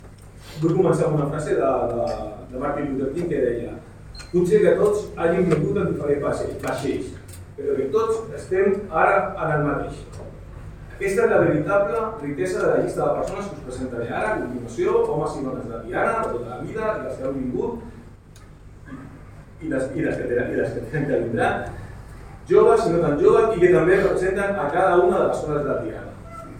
PSC presenta a la Sala d’Exposicions del Casal la candidatura a les eleccions municipals
Per últim, va presentar un per un a la resta de membres de la candidatura: